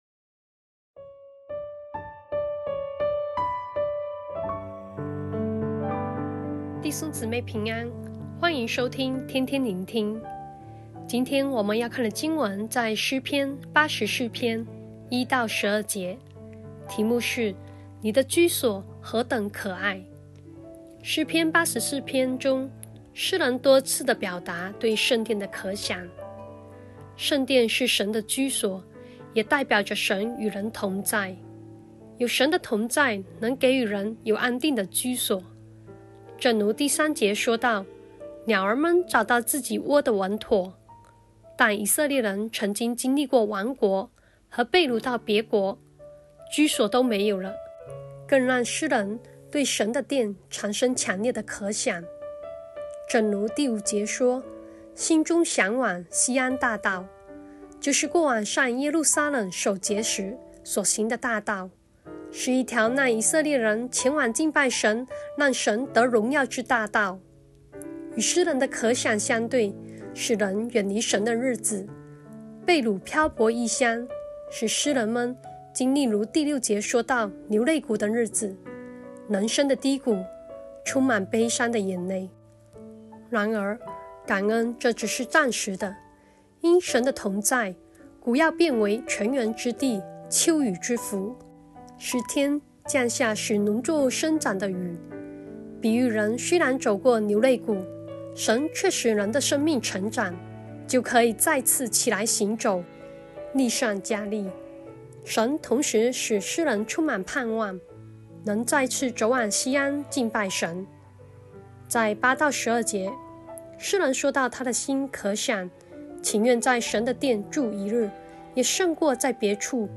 普通話錄音連結🔈